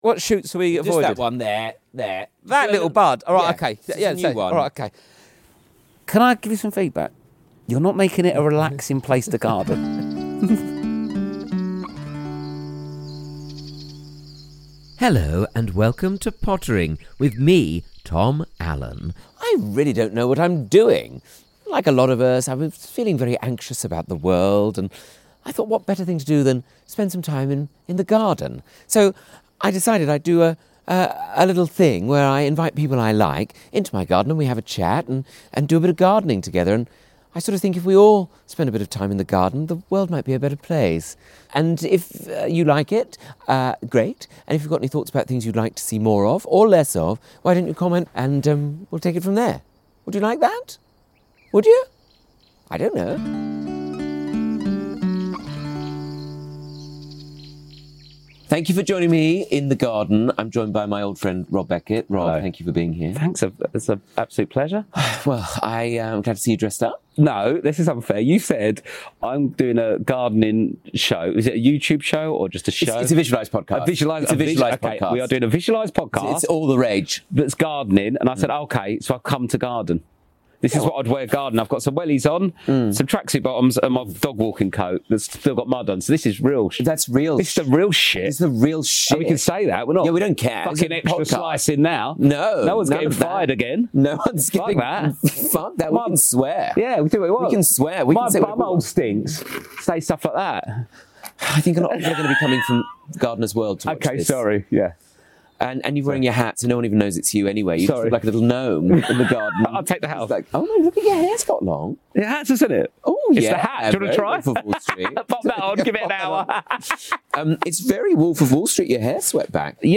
Who better to kick things off than my brilliant friend, the hilarious Rob Beckett.